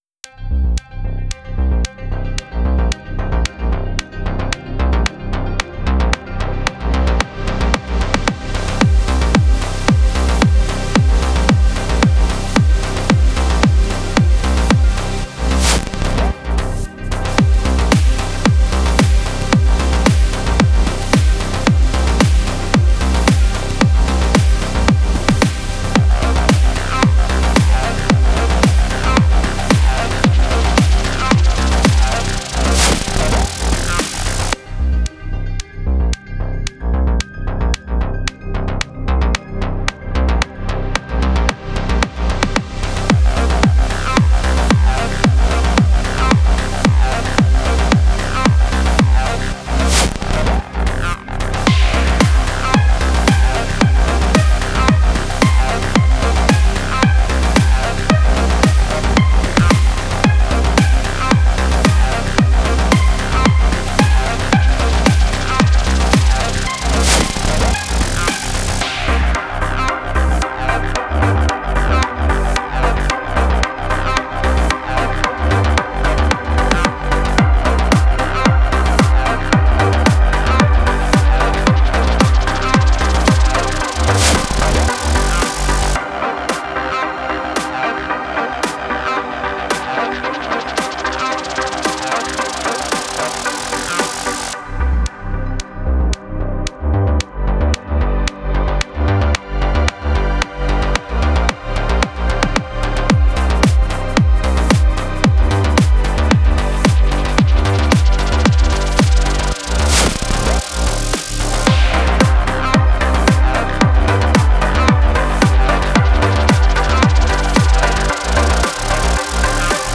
ELECTRO S-Z (34)